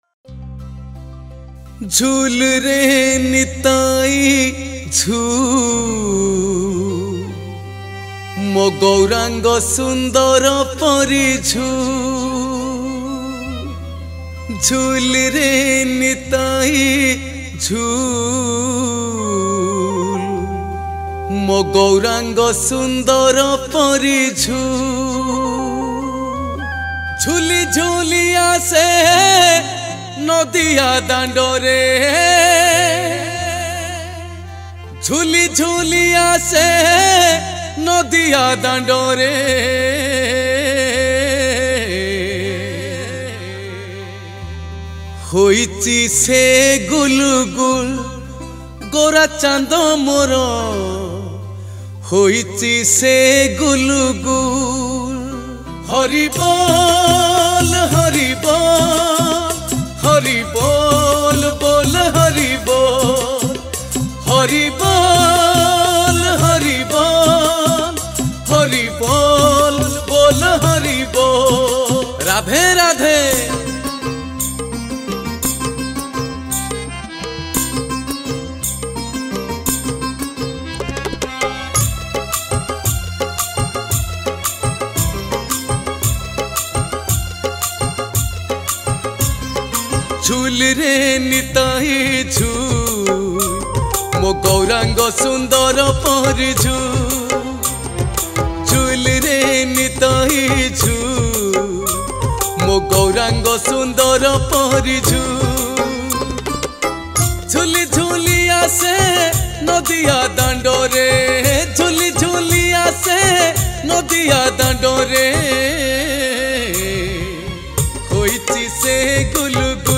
Recording & Mixing : Sun Studio